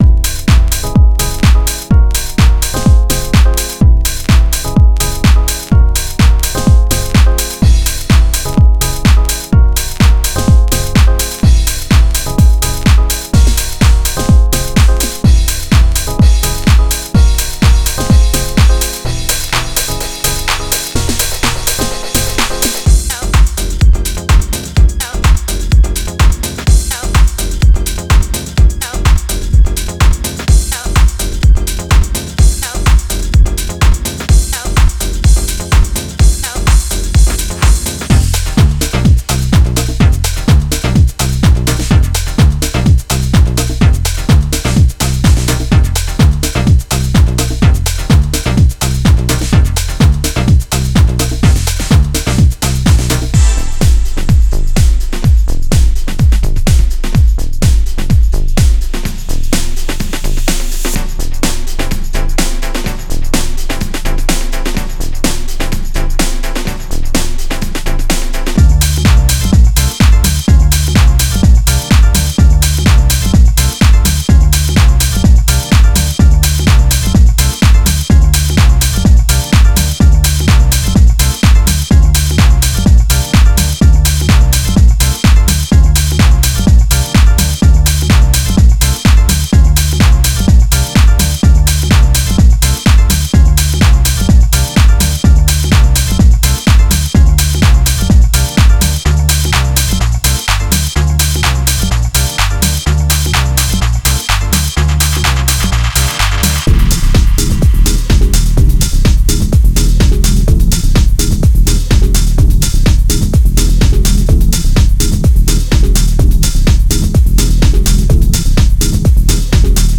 Genre:Classic House
デモサウンドはコチラ↓